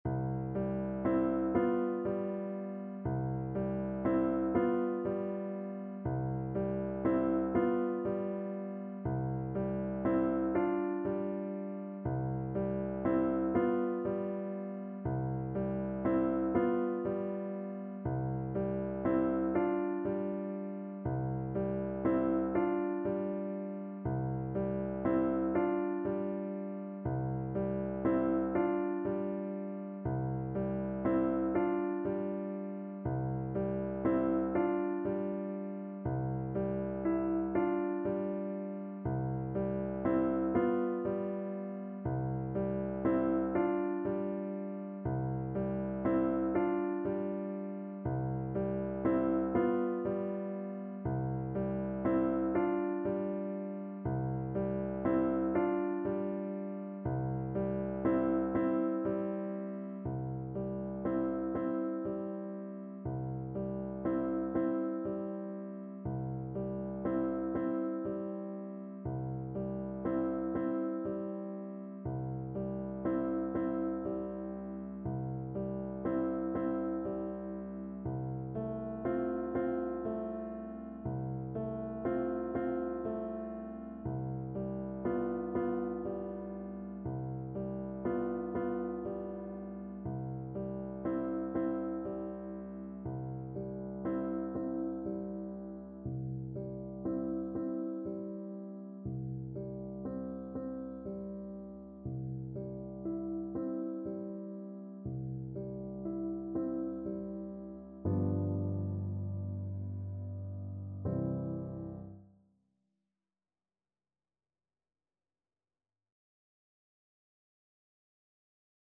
Classical Chopin, Frédéric Berceuse, Op.57 Flute version
Play (or use space bar on your keyboard) Pause Music Playalong - Piano Accompaniment Playalong Band Accompaniment not yet available transpose reset tempo print settings full screen
Flute
6/8 (View more 6/8 Music)
C major (Sounding Pitch) (View more C major Music for Flute )
Andante =120
Classical (View more Classical Flute Music)